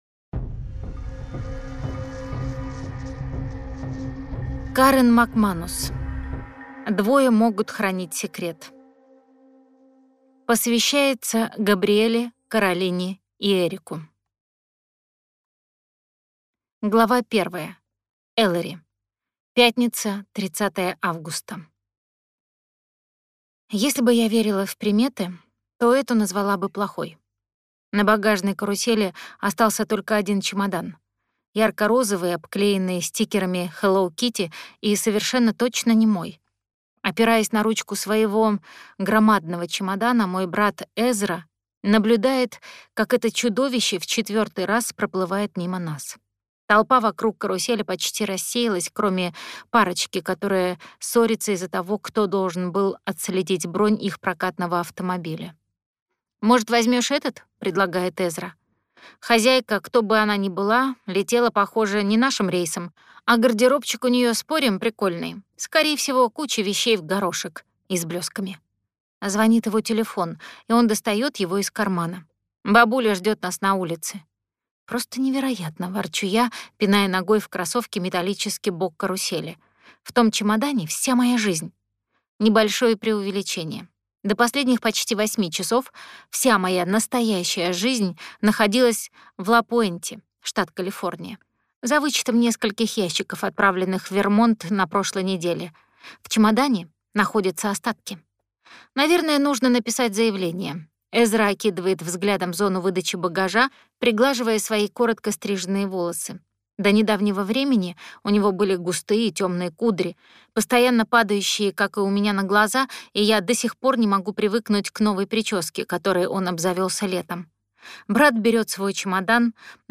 Аудиокнига Двое могут хранить секрет | Библиотека аудиокниг